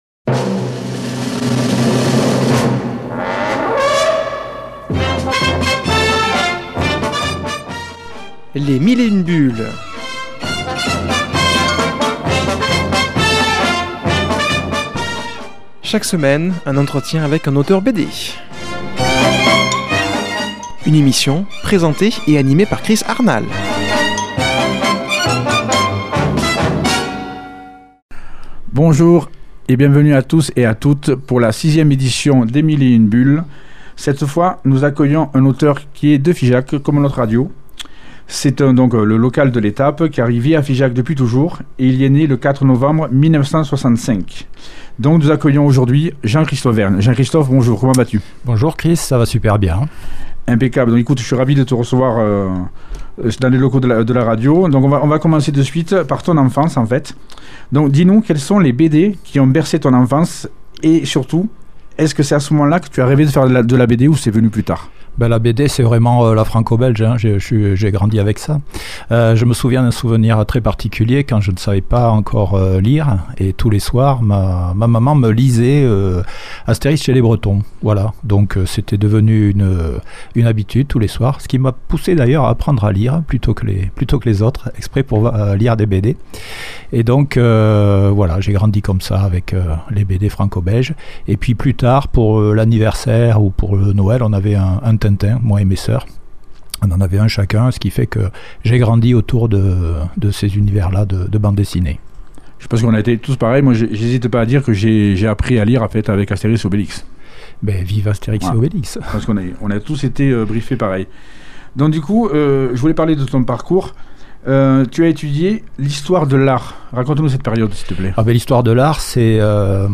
invité au studio